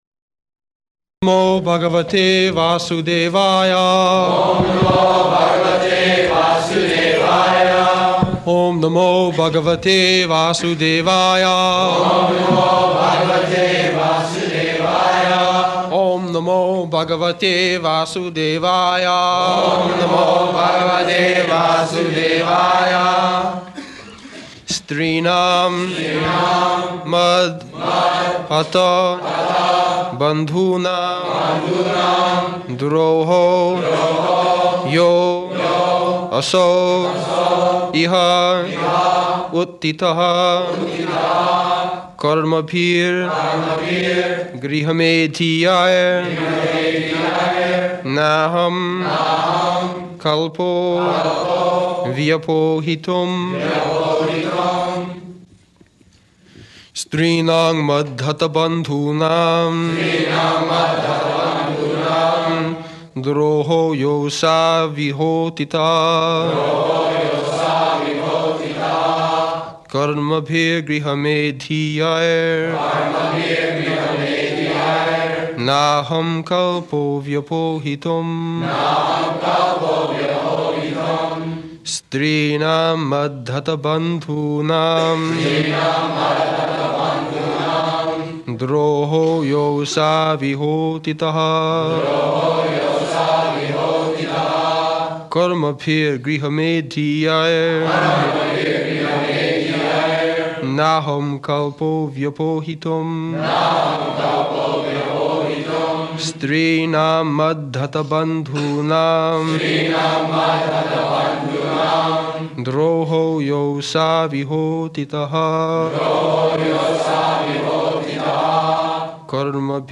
May 13th 1973 Location: Los Angeles Audio file
[leads chanting of verse] [Prabhupāda and devotees repeat] strīṇāṁ mad-dhata-bandhūnāṁ droho yo 'sāv ihotthitaḥ karmabhir gṛhamedhīyair nāhaṁ kalpo vyapohitum [ SB 1.8.51 ] [break] Prabhupāda: Word meaning.